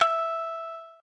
shamisen_e.ogg